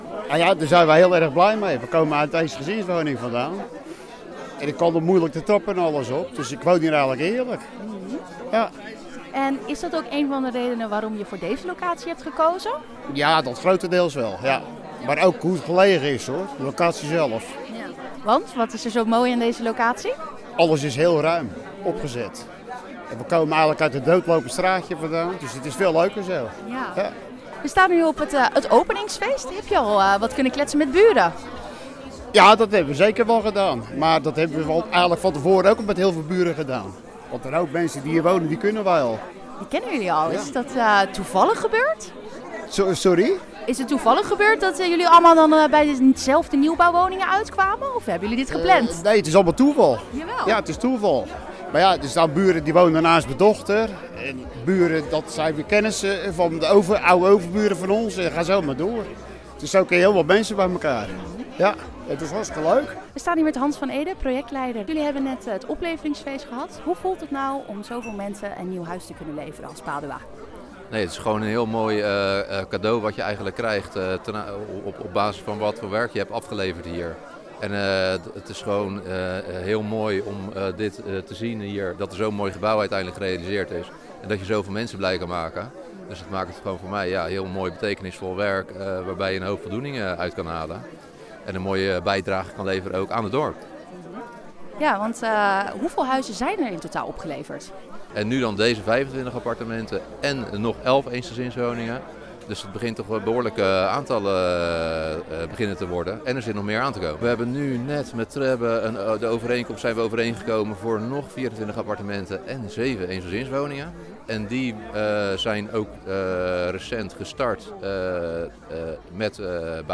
[AUDIO] Feestelijke middag bij opleveringsfeest Padua - Bollenstreek Omroep